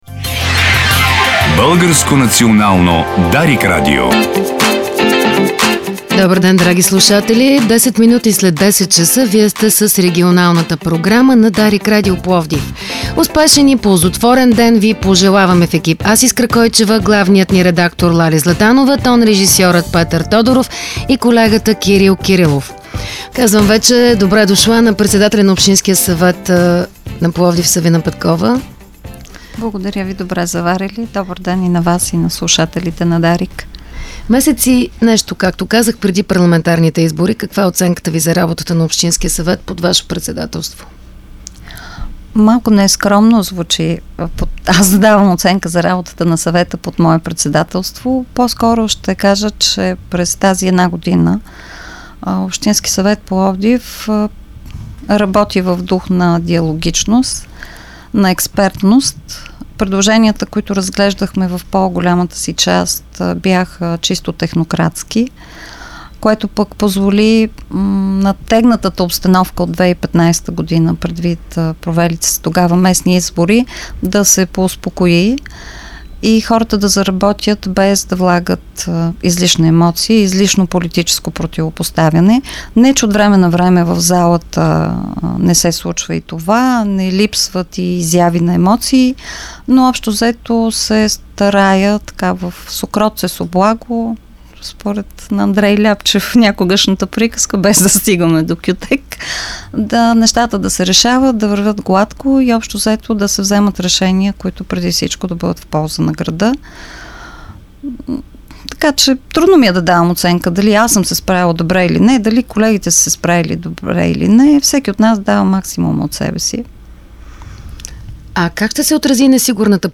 През изминалата една година Общинският съвет на Пловдив работи абсолютно диалогично. Това заяви в обширно интервю в студиото на Дарик радио председателят на местния парламент Савина Петкова.